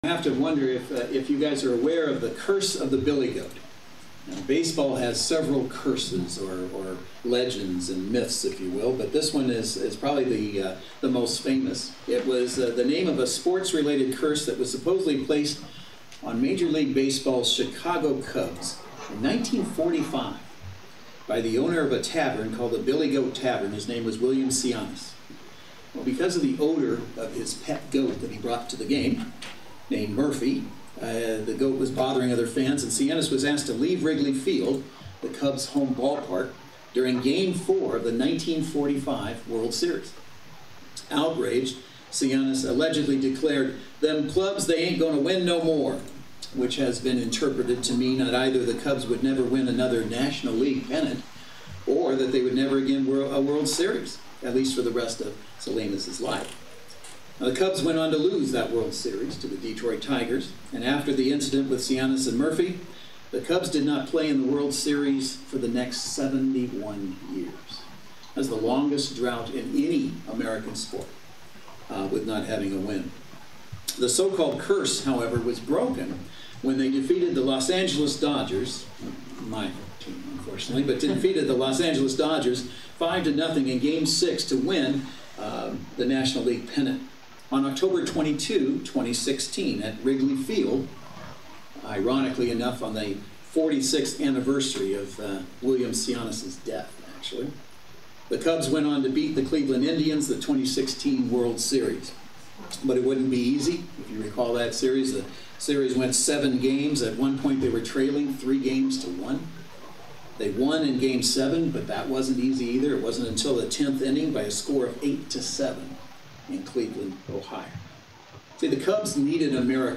Service Type: Saturday Worship Service